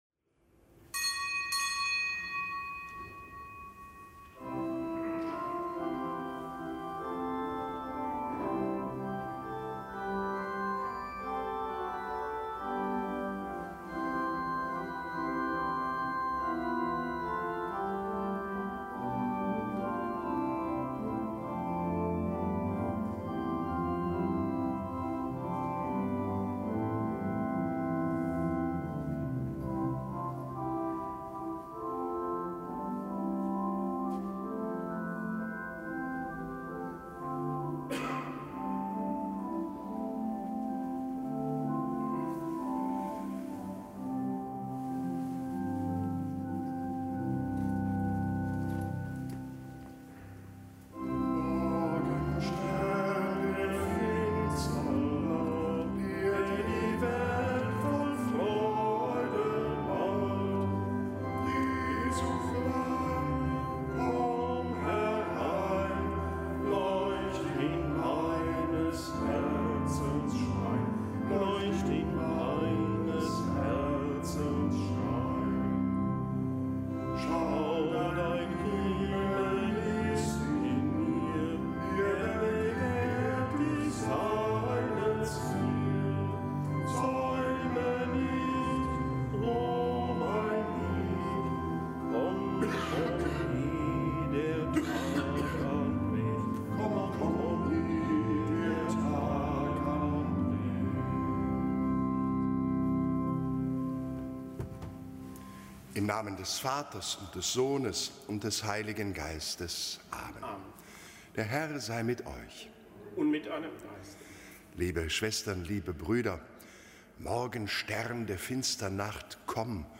Kapitelsmesse am Donnerstag der dritten Woche im Jahreskreis